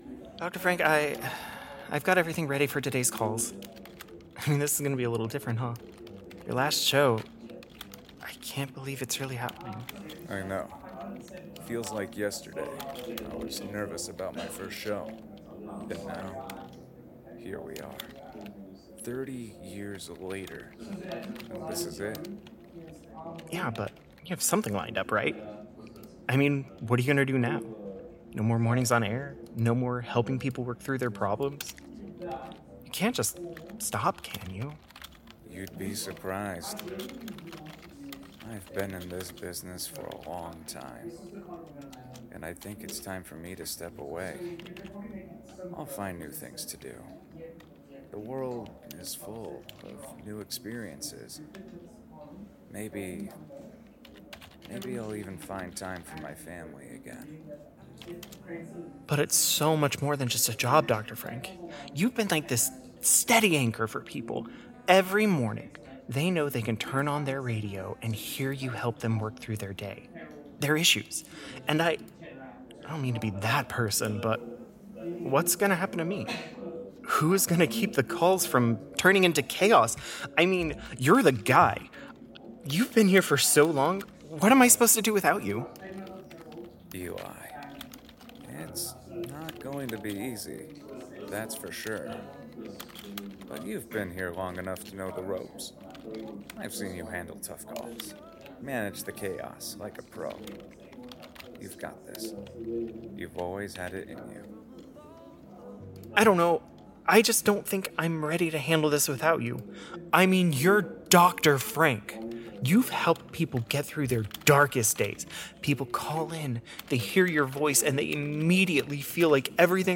Tales from the Janitor: Chilling Urban Legends from Pennsylvania | Horror Audio Drama
Mysteries... all told by your local mop-carrying menace who knows way too much about things that go bump under fluorescent lighting.
From Gettysburg ghosts to strange whispers in abandoned steel mills, Pennsylvania’s got more hauntings than potholes… and that’s saying something. Every tale drips with atmosphere, slow building dread, and that slightly sarcastic janitor humor you only get from someone who’s swept up more paranormal messes than spilled chocolate milk.